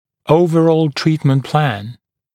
[‘əuvərɔːl ‘triːtmənt plæn][‘оувэро:л ‘три:тмэнт плэн]общий план лечения (целиком)